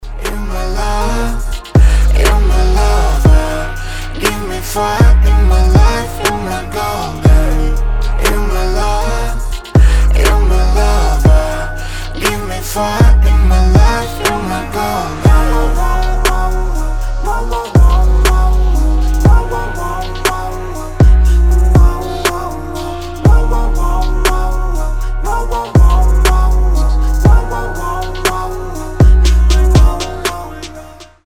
• Качество: 320, Stereo
поп
спокойные
дуэт
Trap